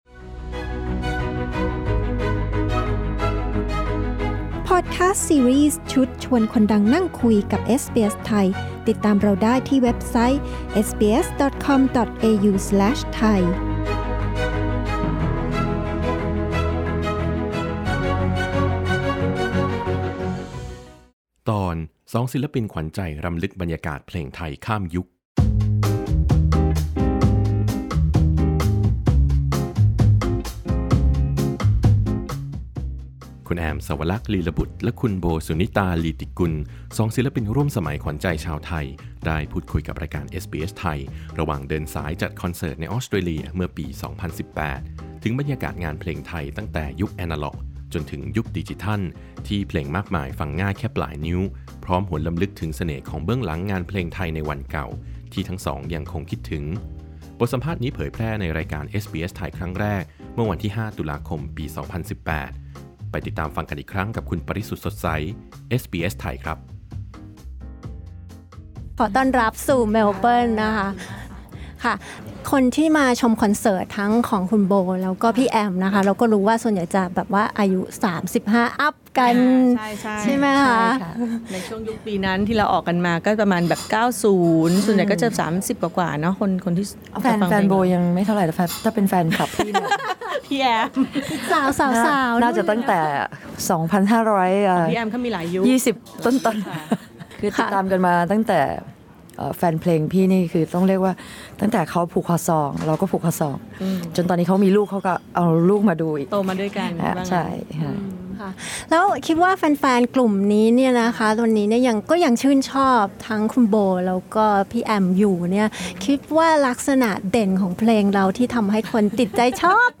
คุณแอม เสาวลักษณ์ ลีลบุตร และคุณโบ สุนิตา ลีติกุล สองศิลปินร่วมสมัยขวัญใจชาวไทย ได้พูดคุยกับรายการเอสบีเอส ไทย ระหว่างเดินสายจัดคอนเสิร์ตในออสเตรเลีย เมื่อปี 2018 ถึงบรรยากาศงานเพลงไทยตั้งแต่ยุคแอนะล็อก จนถึงยุคดิจิทัลที่เพลงมากมายฟังง่ายแค่ปลายนิ้ว…